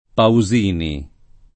[ pau @& ni ]